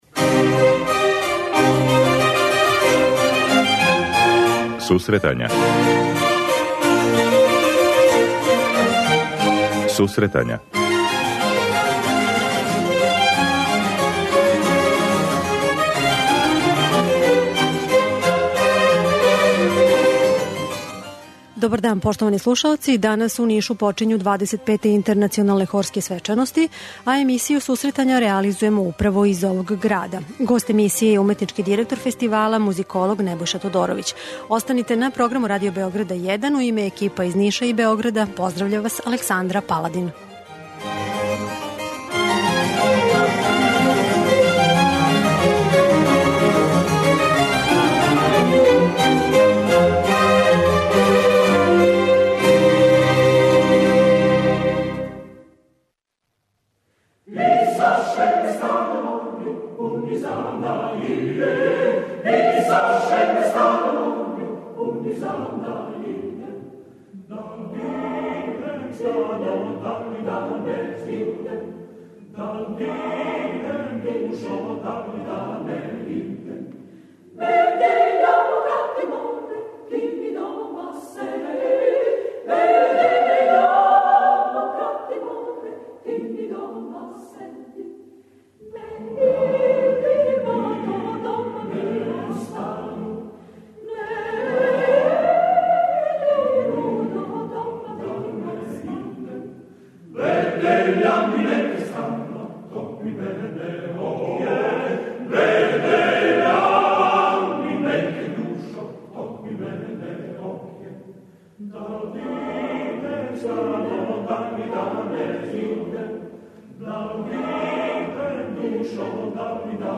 У Нишу данас почињу 'Интернационалне хорске свечаности' па се и данашња емисија реализује из овог града.